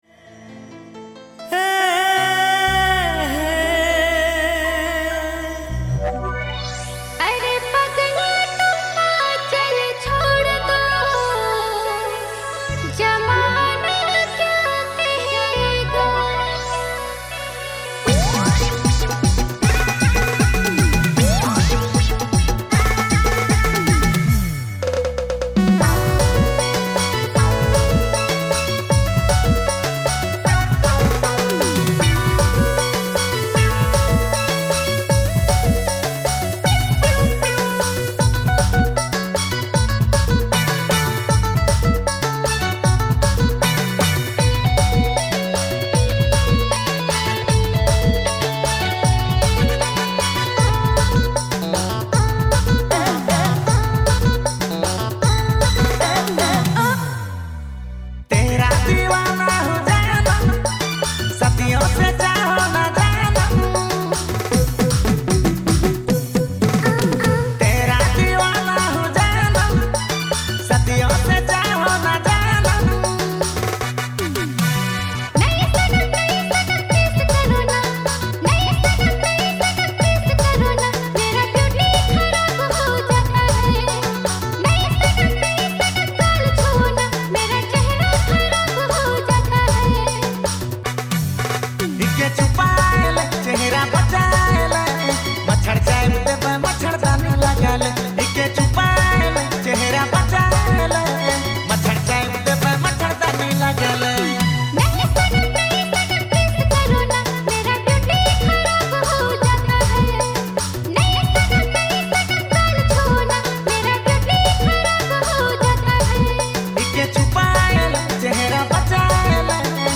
energetic rhythms